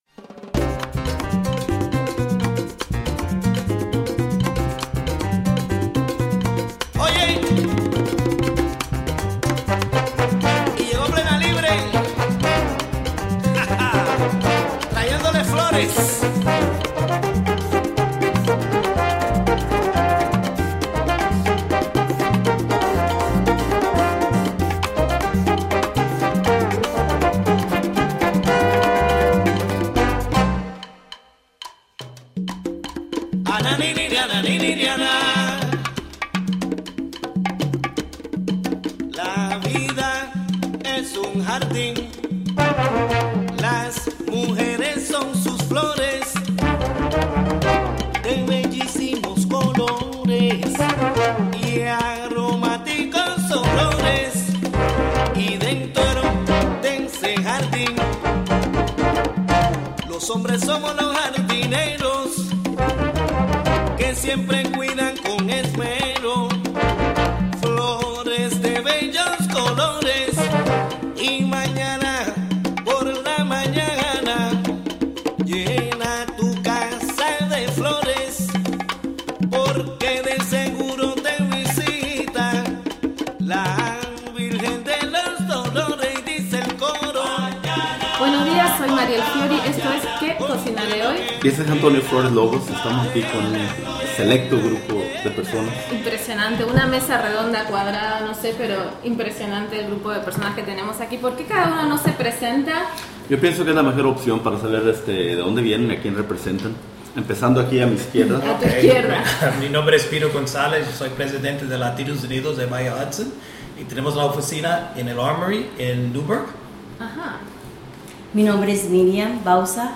round table discussion